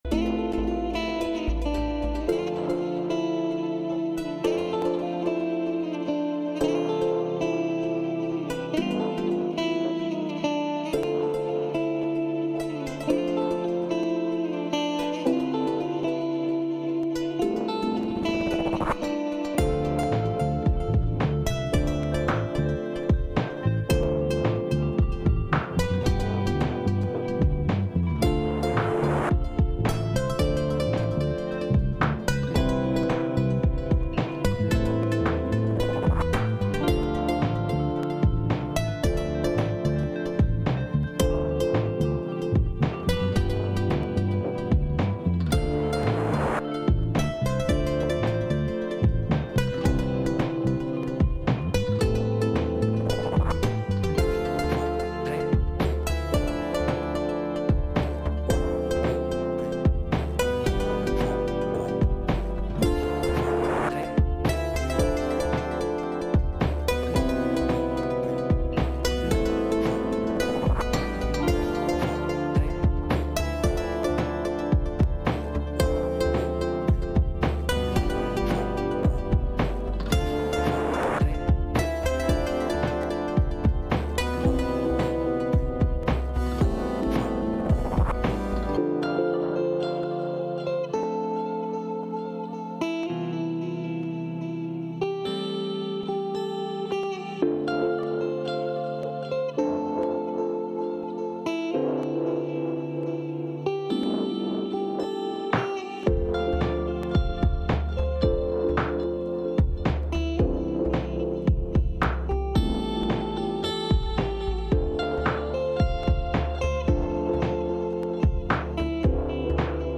A message from the series "Take a Breath."
ICC-Australia-Day-Online-Church-Service.mp3